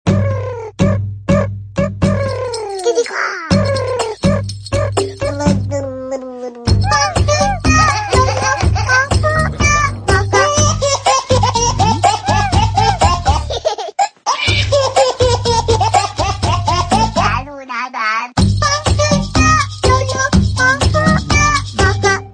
Kategorien: Lustige